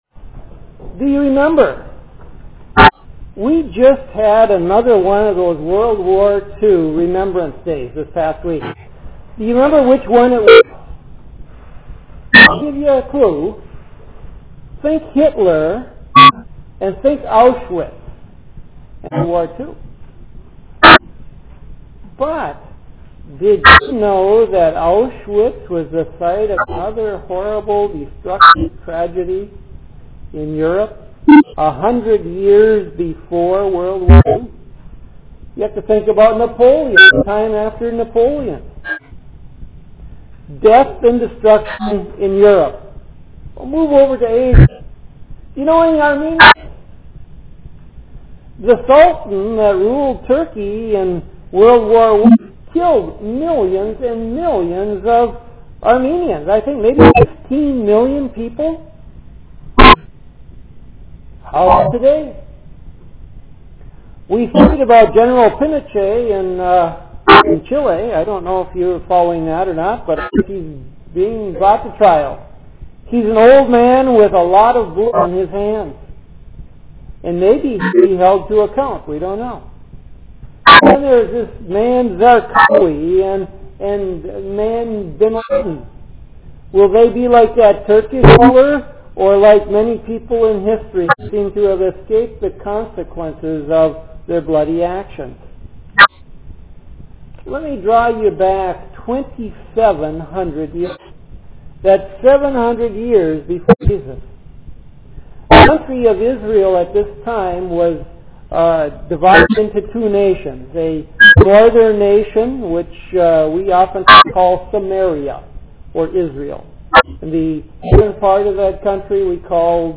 Zion Lutheran Church - Sermons